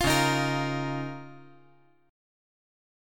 Db6add9 Chord
Listen to Db6add9 strummed